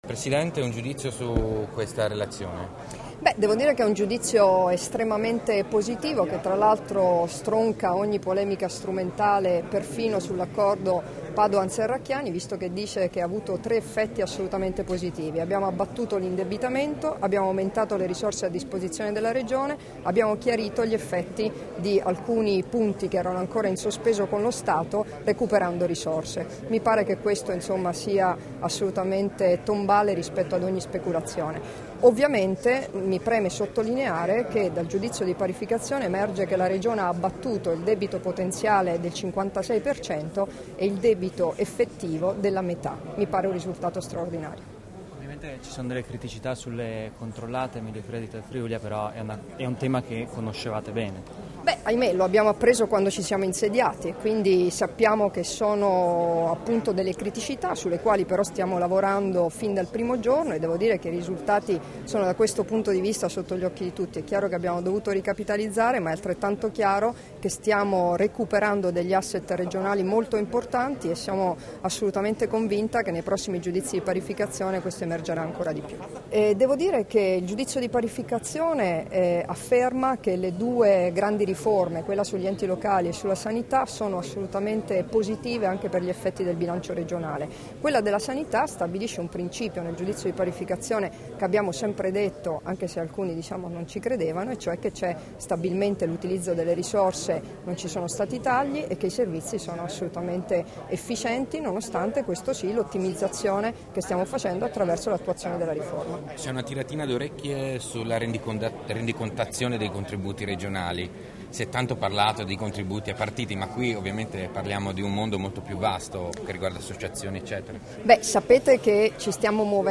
Dichiarazioni di Debora Serracchiani (Formato MP3) [2661KB]
rilasciate a margine dell'udienza pubblica della Sezione di controllo regionale della Corte dei Conti, a Trieste il 16 luglio 2015